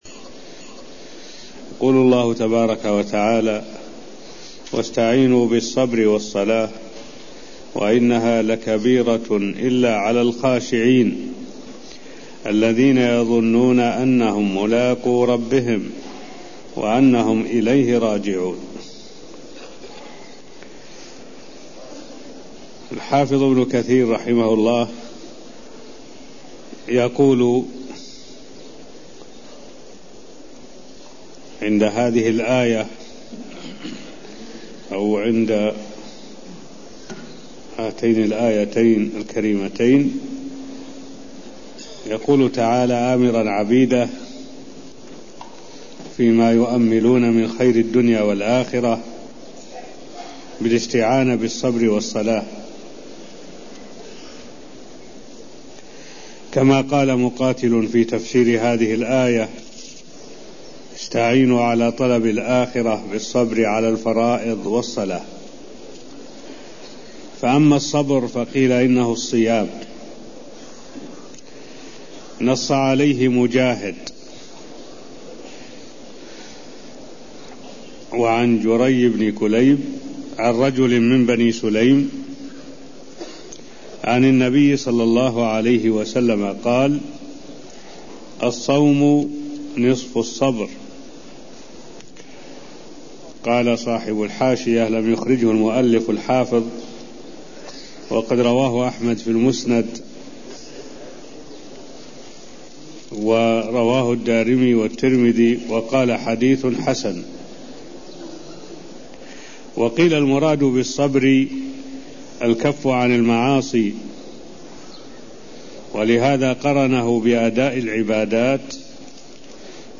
المكان: المسجد النبوي الشيخ: معالي الشيخ الدكتور صالح بن عبد الله العبود معالي الشيخ الدكتور صالح بن عبد الله العبود تفسير قول الله تعالى واستعينوا بالصبر والصلاة (0032)